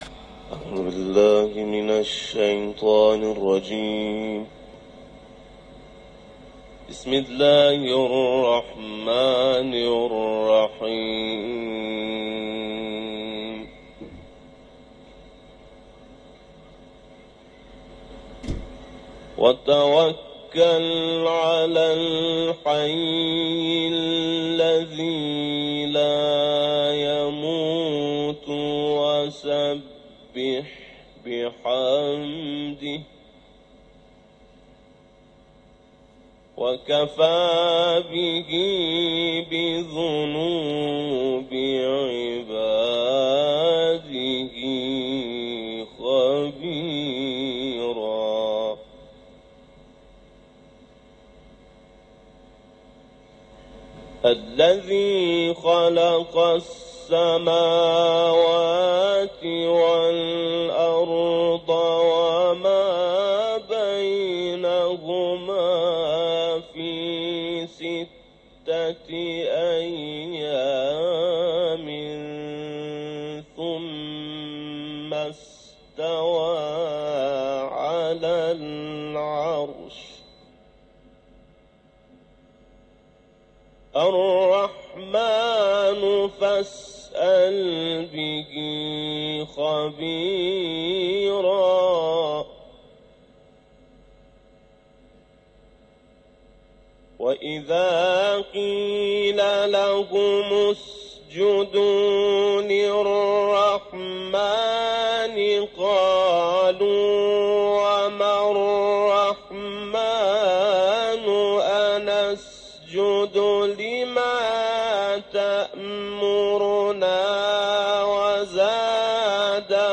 تلاوت
حرم مطهر رضوی ، سوره فرقان